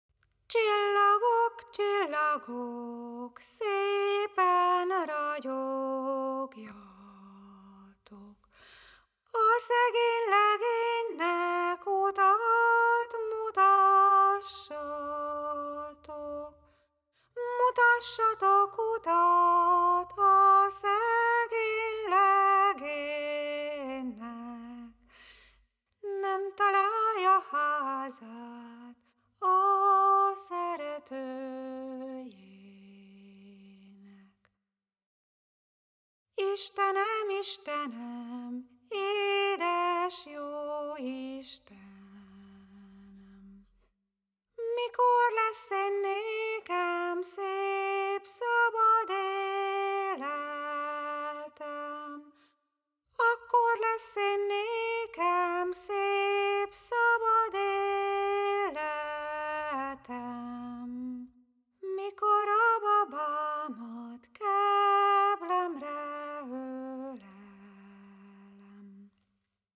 népdal